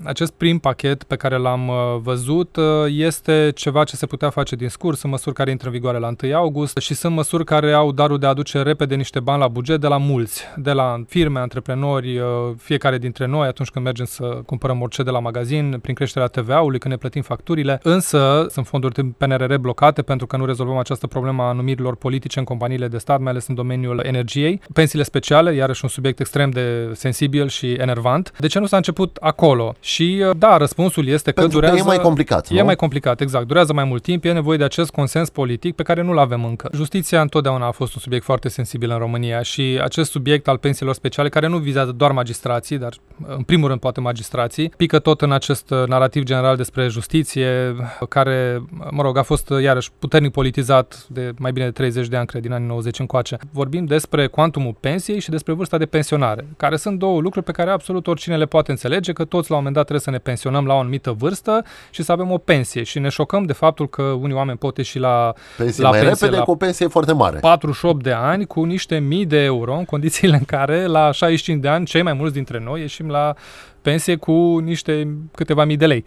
Invitat la Radio Cluj